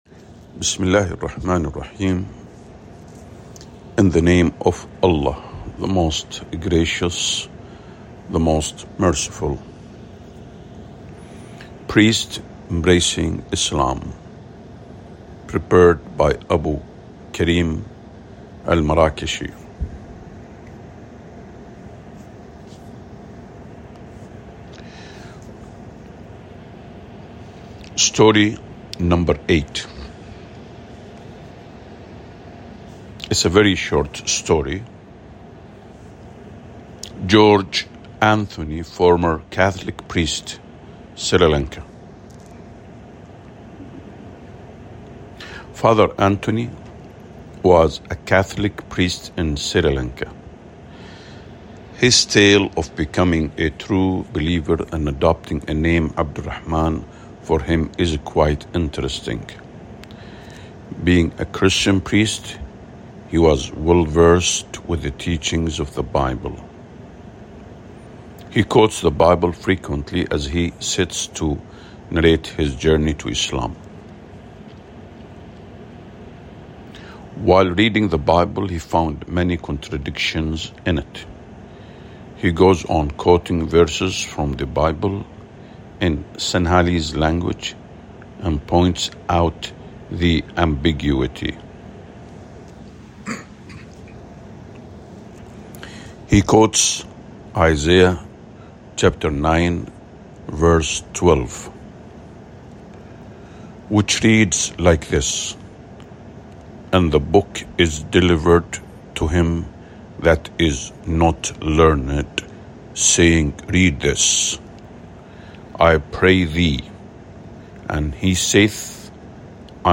Audiobook
priests-embracing-islam_audiobook_english_8.mp3